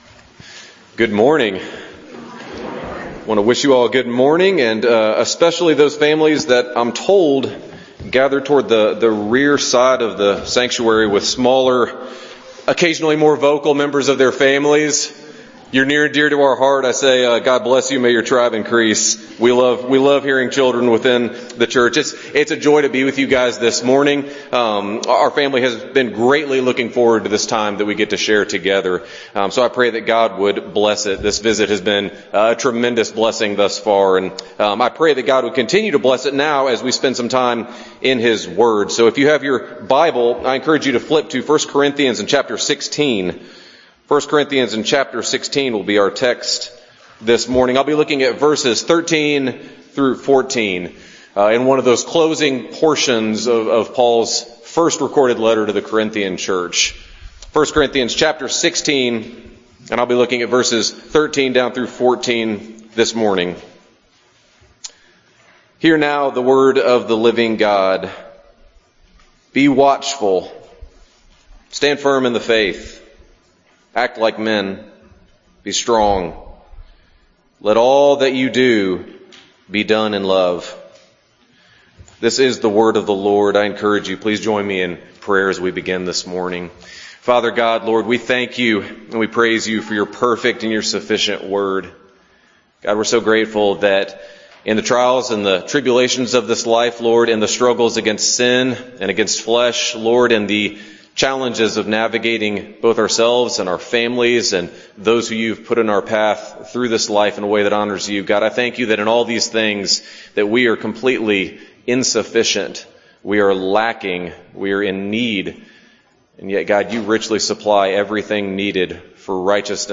Guest Pastor Passage: 1 Corinthians 16:13-14